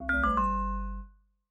steeltonguedrum_c1ag.ogg